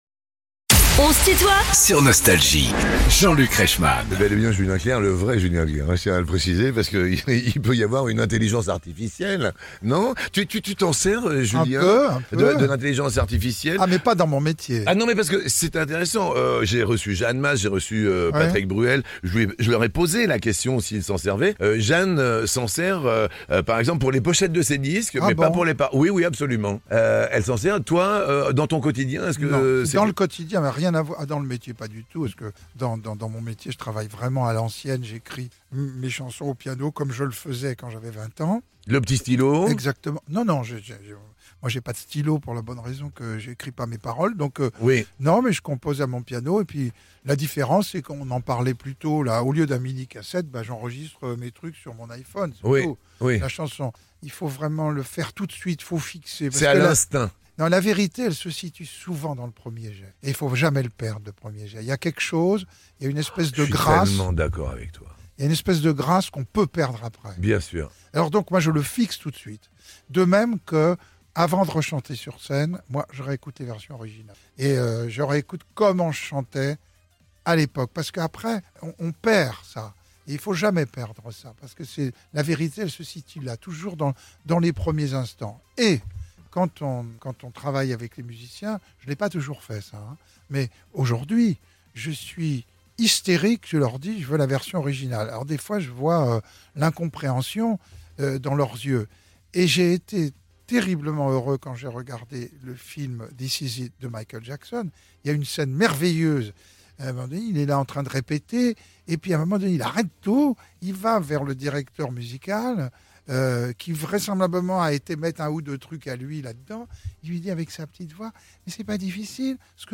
Julien Clerc est l'invitée de "On se tutoie ?..." avec Jean-Luc Reichmann
Les plus grands artistes sont en interview sur Nostalgie.